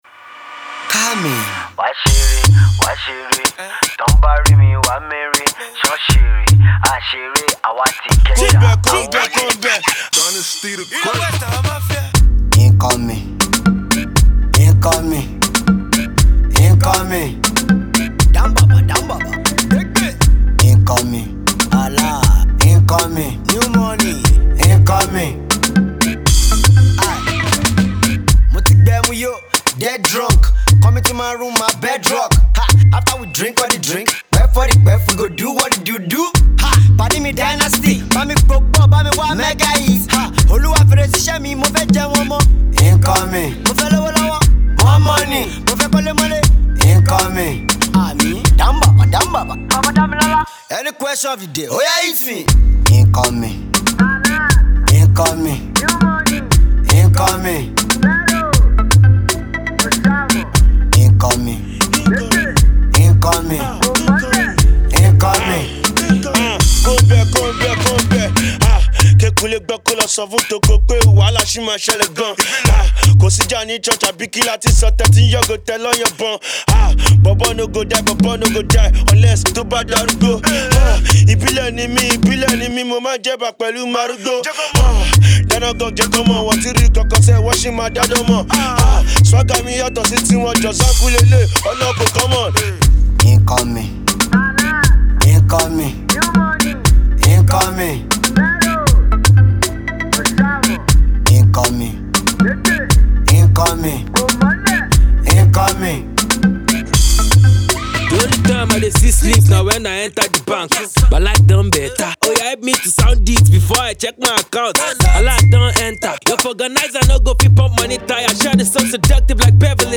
rides heavily on the Shaku/Zanku wave.
The radio-friendly tune